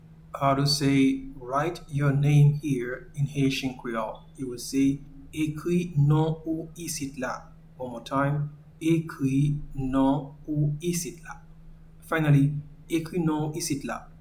Pronunciation:
English and Haitian Creole voice transcription:
Write-your-name-in-Haitian-Creole-Ekri-non-ou-isit-la.mp3